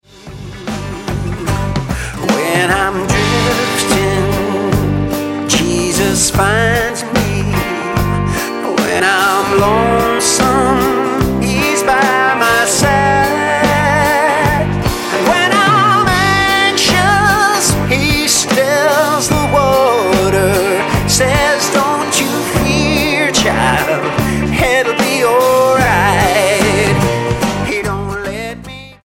STYLE: Blues
satisfyingly raw collection of original gospel blues numbers